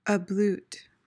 PRONUNCIATION: (uh-BLOOT) MEANING: verb tr., intr.: To bathe or to wash a part of the body.
ablute.mp3